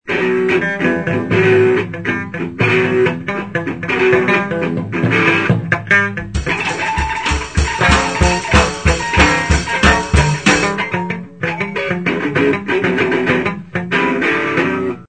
improvisations